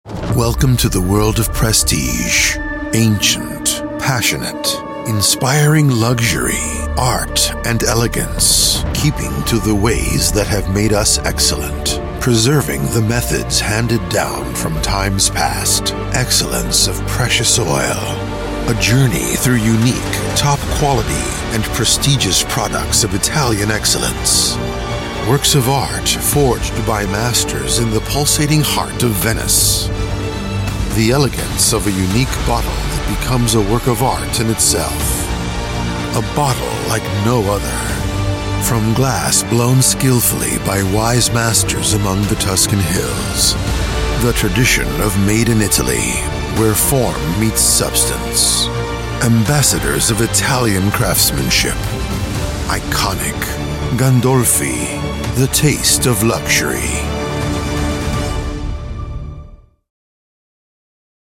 Male
English (North American)
Adult (30-50), Older Sound (50+)
All produced in a professional broadcast quality studio.
Television Spots